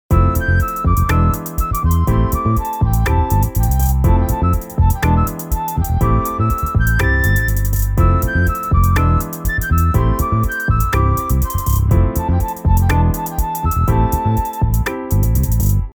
今回は、フルートの音色でメロディーを作成したいので、検索ボックスに「flute」と入力しました。
現在作成中のメロディーは、後でボーカルに差し替える予定のため、ボリュームの強弱をつけず、一定のボリュームに整えます。
▶メロディーを加え調整を行なった状態
Logic11-Melody.mp3